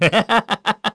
Zafir-Vox_Happy2_kr.wav